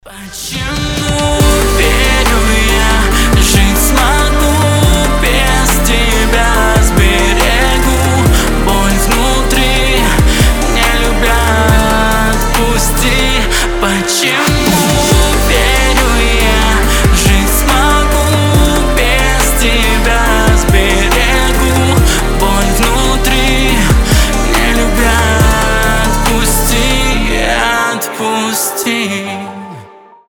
красивые
грустные
медленные